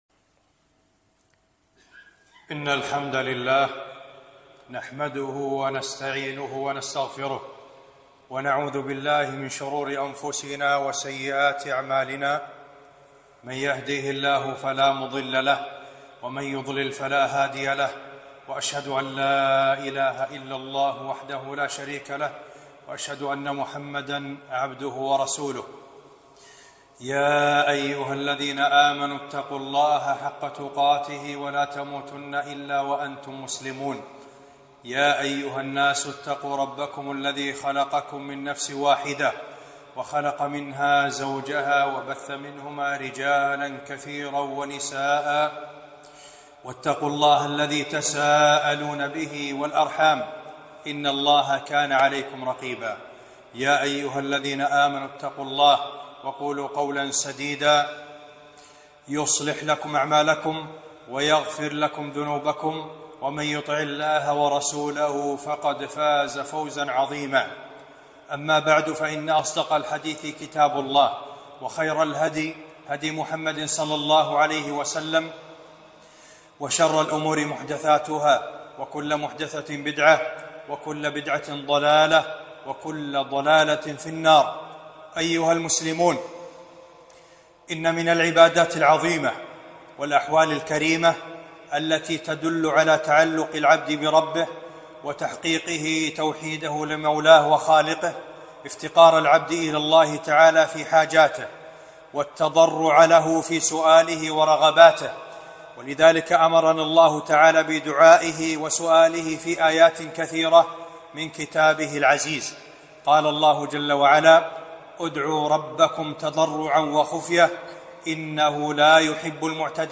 خطبة - منزلة الدعاء وبيان شيء من آدابه